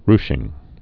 (rshĭng)